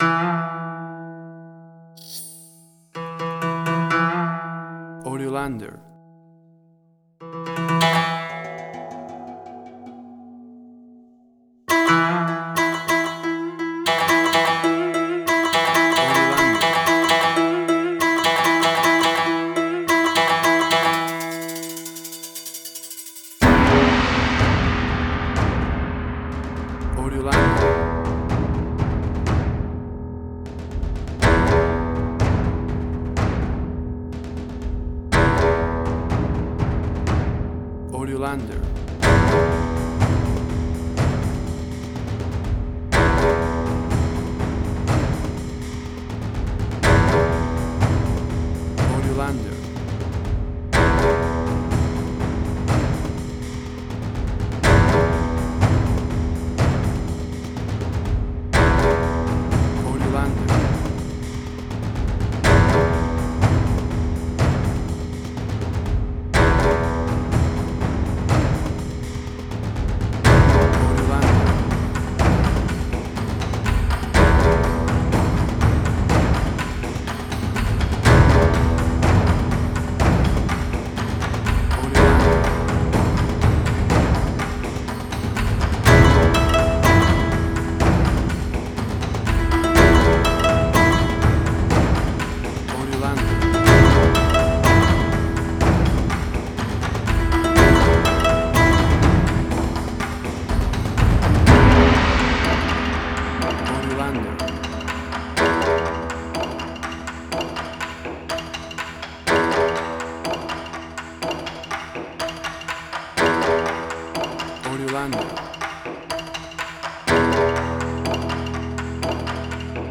Suspense, Drama, Quirky, Emotional.
Tempo (BPM): 62